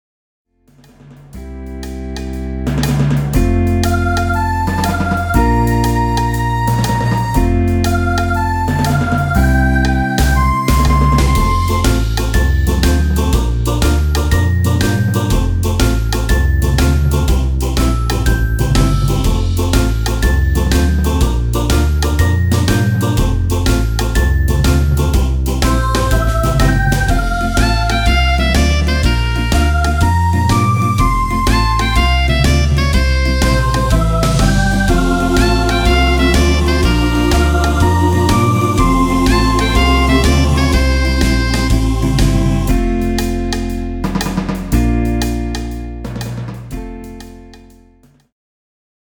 Played on a Lowrey Stardust